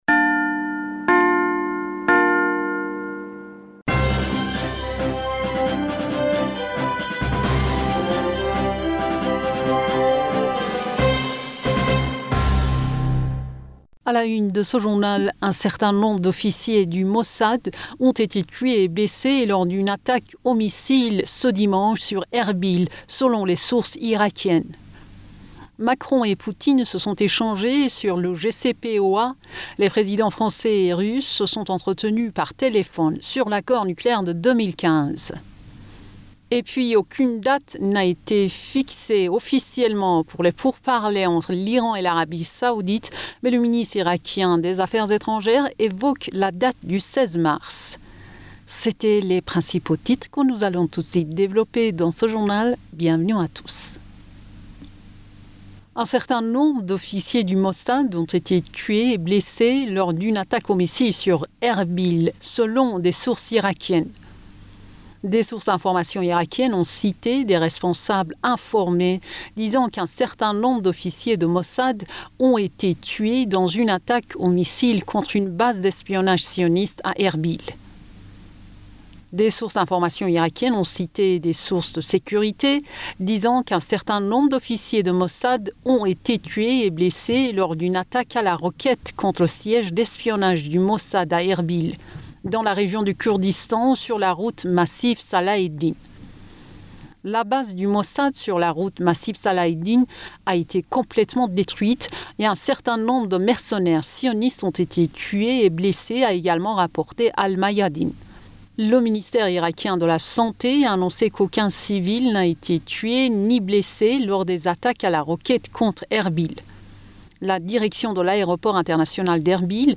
Bulletin d'information Du 13 Mars 2022